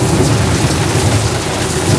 tyres_gravel_trap.wav